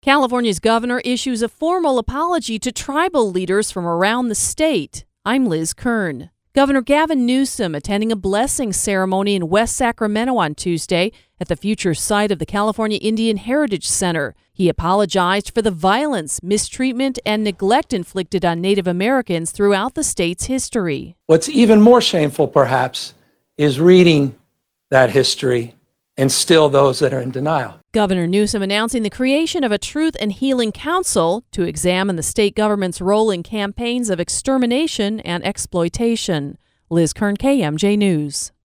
Governor Gavin Newsom attended a blessing ceremony in West Sacramento, at the future site of the California Indian Heritage Center. Newsom apologized Tuesday for the violence, mistreatment and neglect inflicted on Native Americans throughout the state’s history.
The Governor announced the creation of a truth and healing council to examine the state government’s role in campaigns of extermination and exploitation. Click to listen to the report